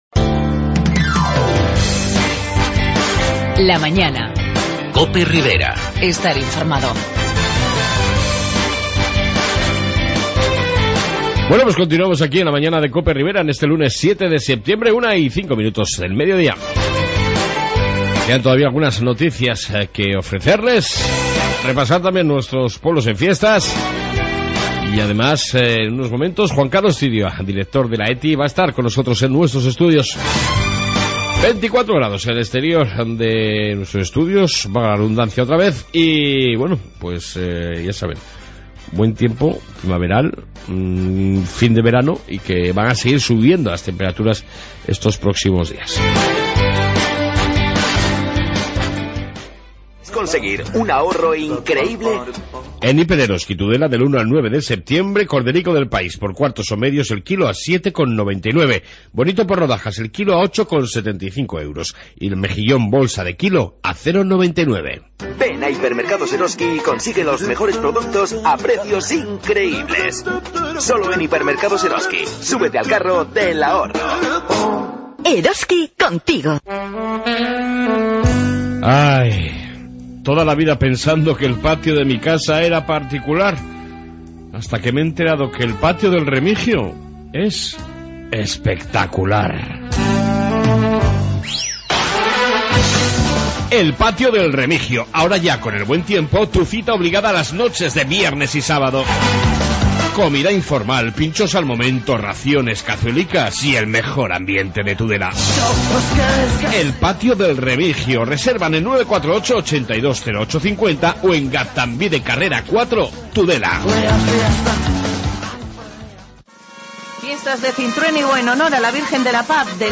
AUDIO: Noticias, Fiestas y Entrevista